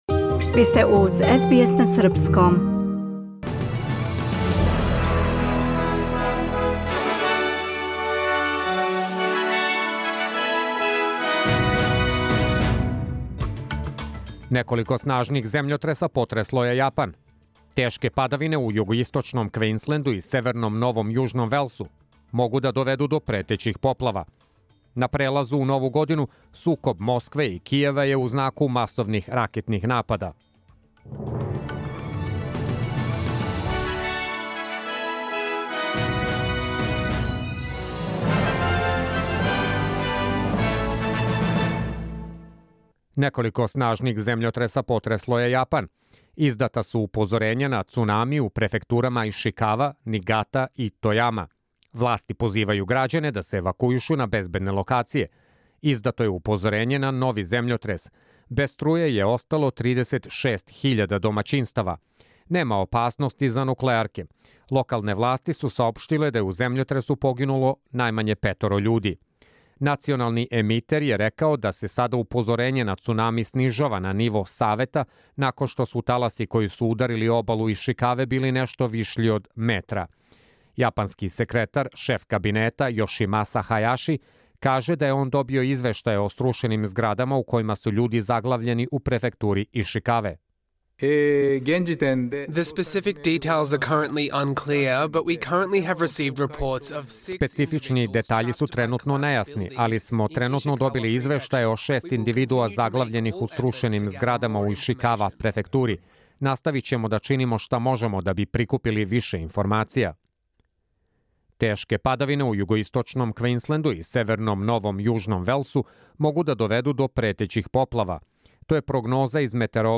Програм емитован уживо 2. јануара 2024. године